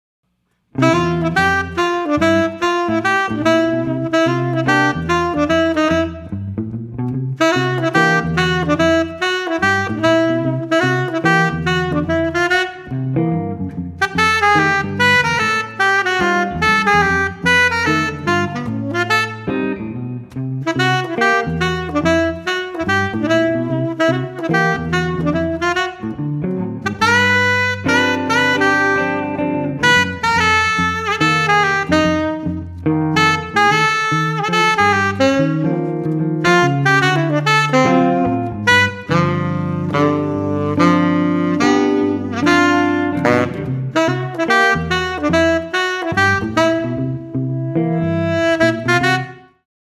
sax solo on this funky blues
guitar